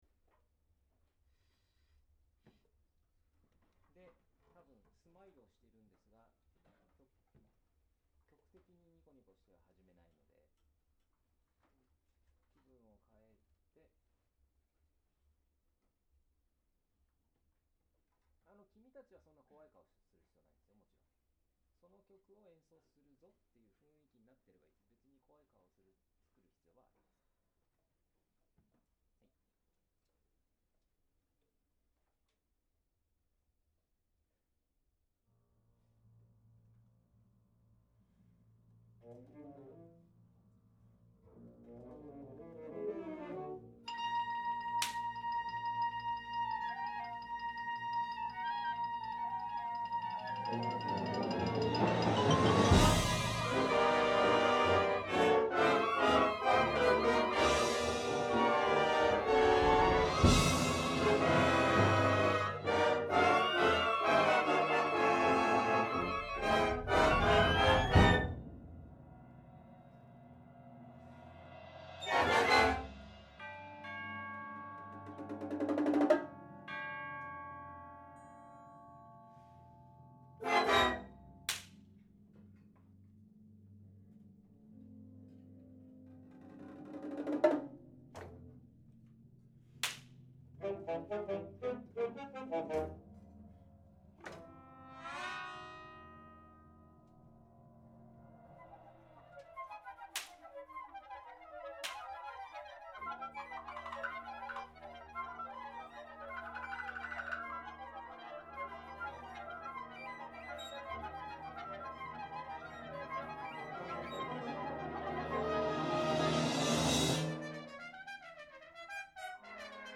ジャンル: 吹奏楽